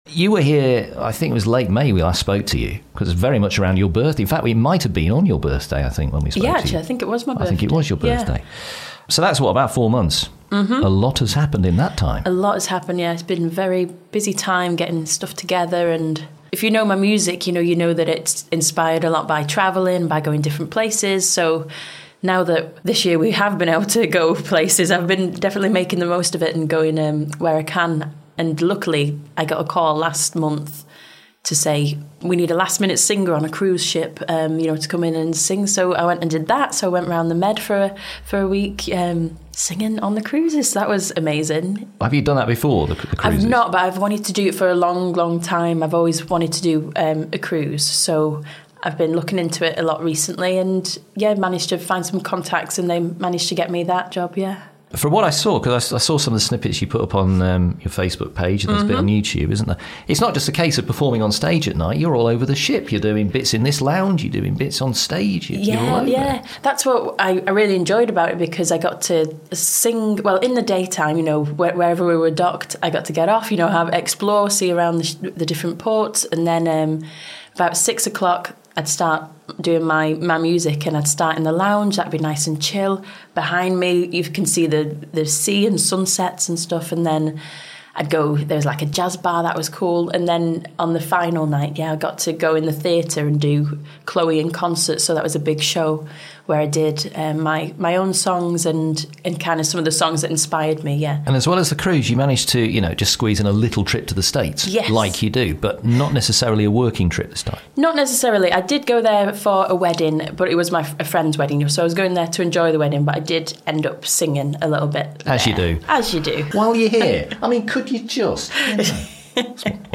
in the Tameside Radio studios.